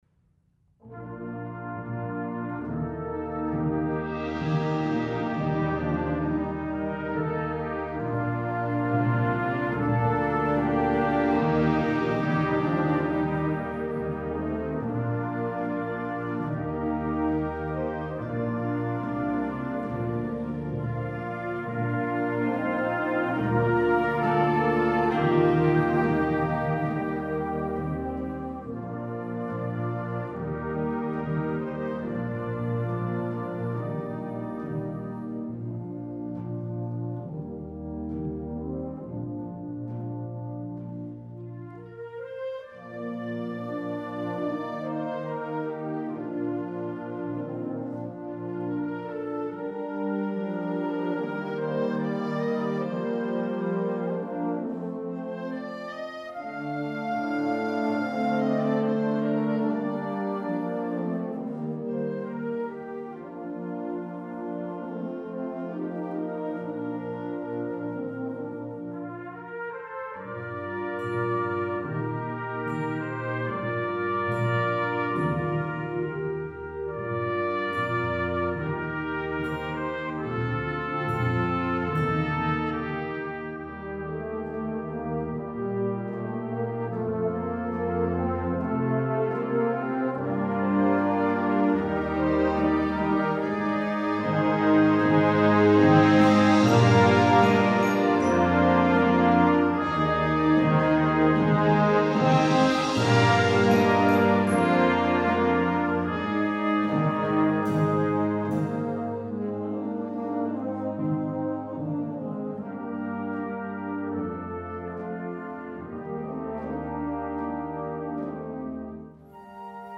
Flex Band